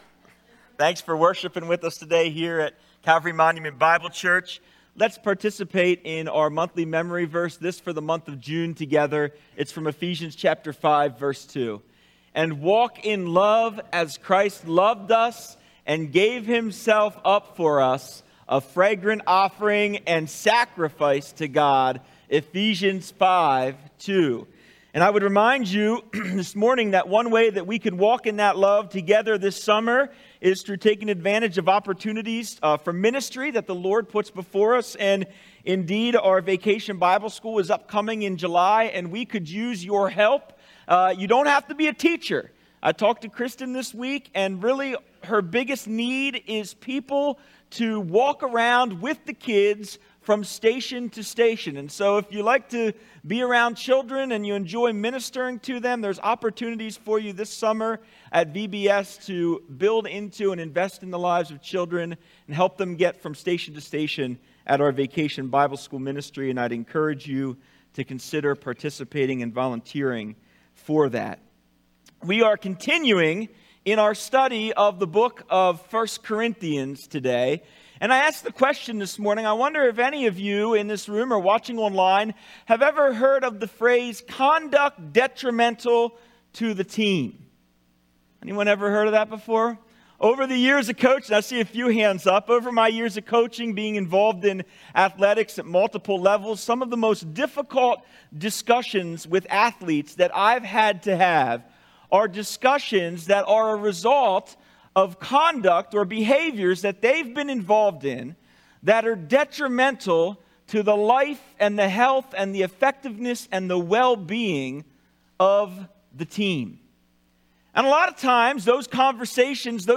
1 Corinthians 5:1-5 Sermon